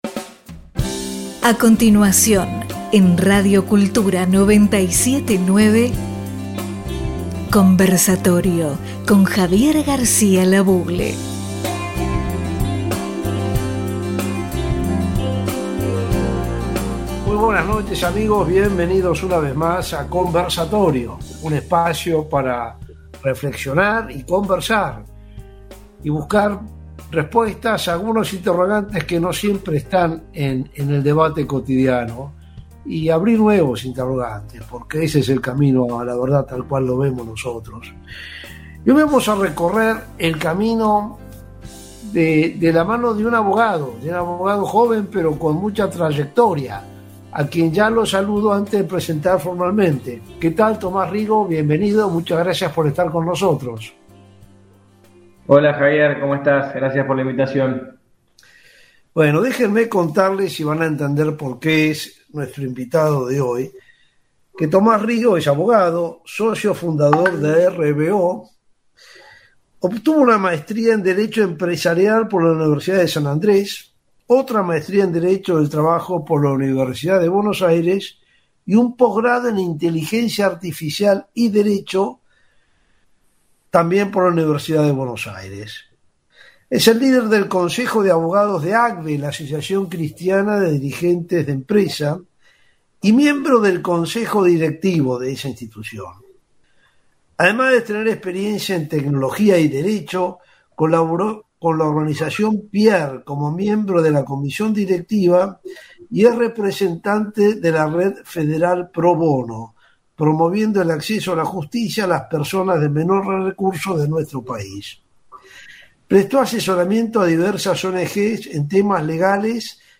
Iniciamos este ciclo radial que hemos llamado Conversatorio, porque precisamente aspiramos a compartir con la audiencia nuestras visiones y reflexiones sobre aquellos temas que consideramos relevantes, pero sentimos que muchas veces están fuera de la agenda y la discusión cotidiana, dominada abrumadoramente por la coyuntura y la anécdota diaria.